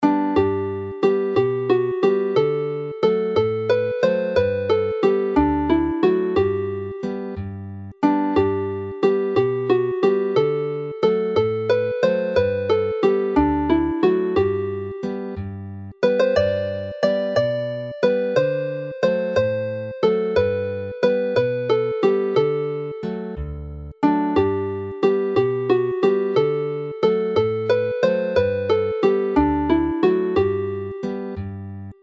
Chwarae'r alaw'n araf
Play the melody slowly